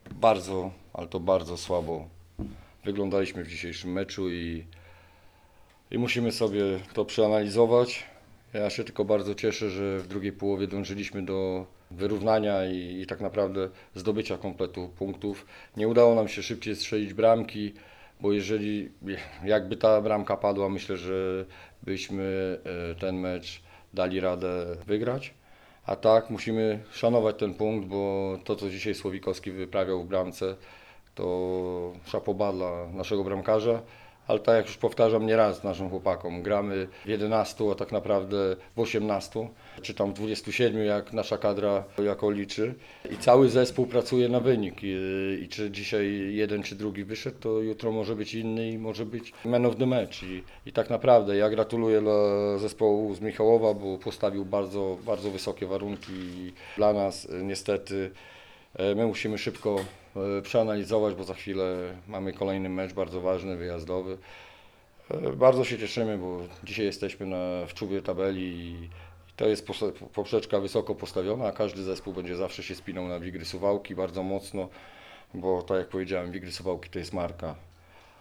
Suwałki – Stadion Miejski